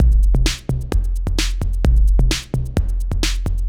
Index of /musicradar/80s-heat-samples/130bpm